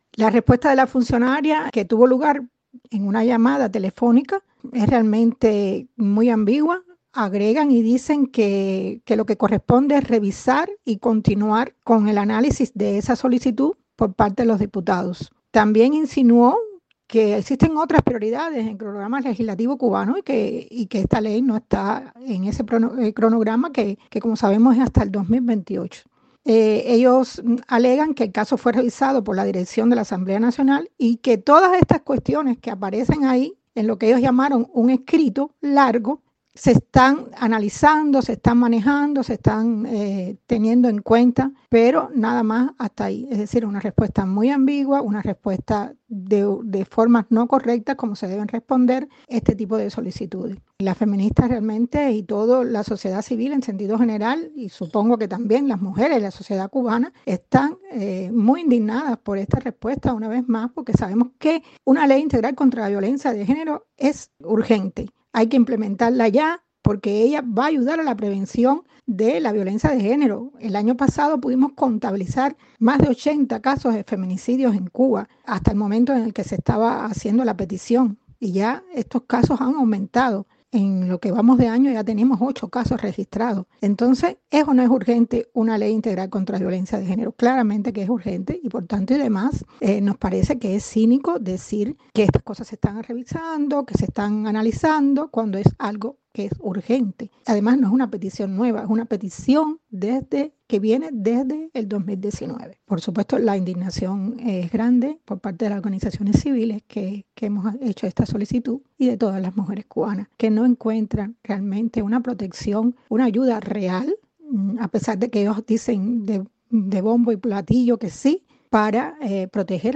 habla con Martí Noticias sobre violencia de género en Cuba